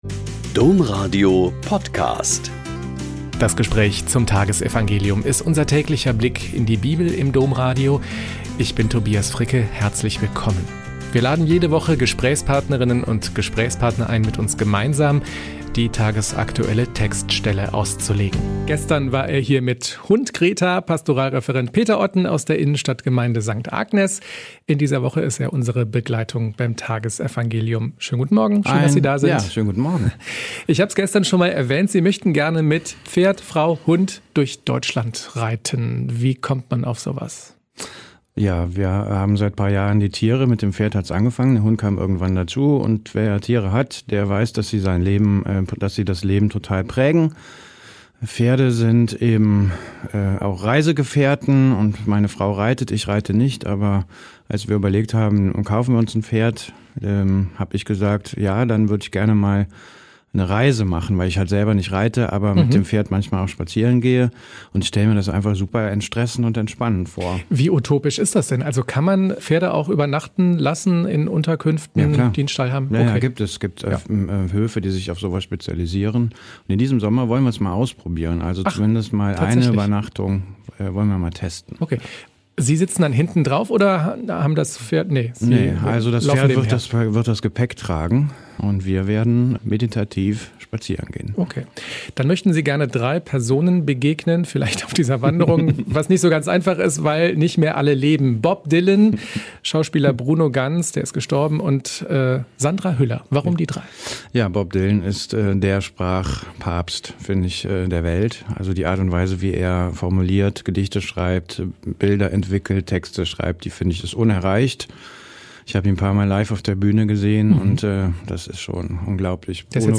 Joh 14,6-14 - Gespräch